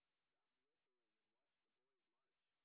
sp23_white_snr10.wav